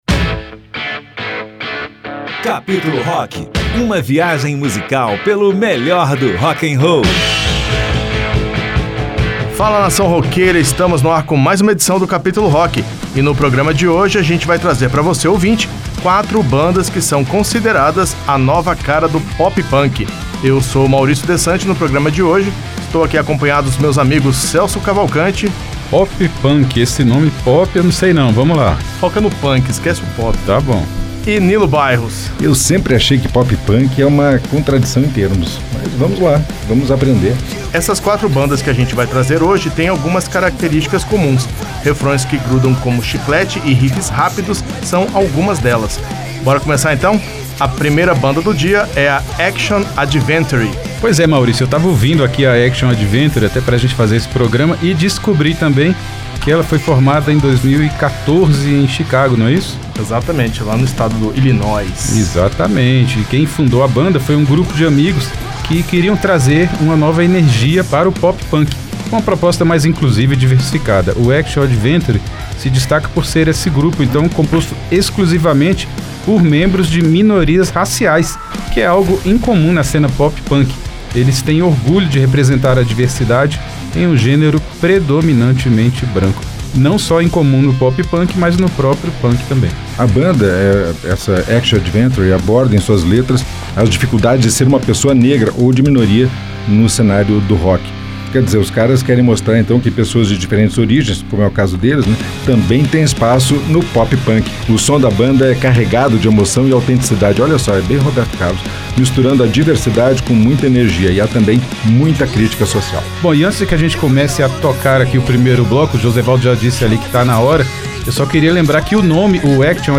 Várias bandas têm revitalizado o estilo, como muita energia e um apelo inédito à diversidade étnica e de gênero.